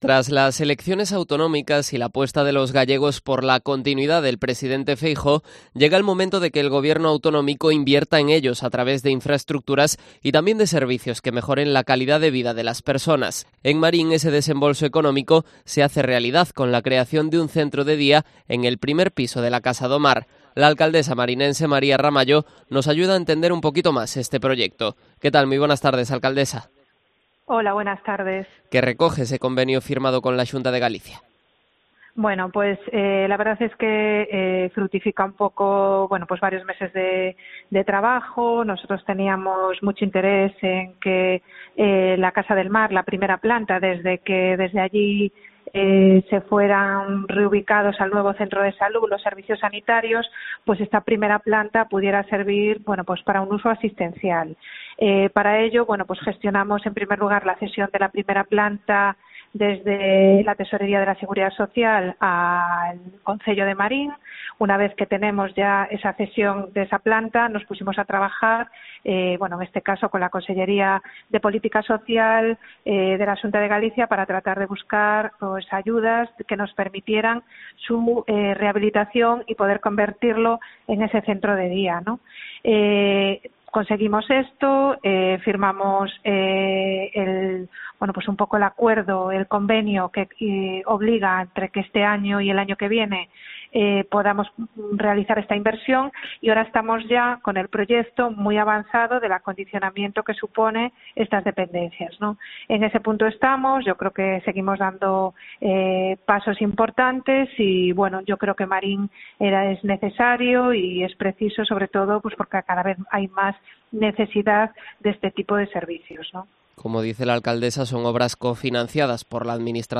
Entrevista a la alcaldesa de Marín, María Ramallo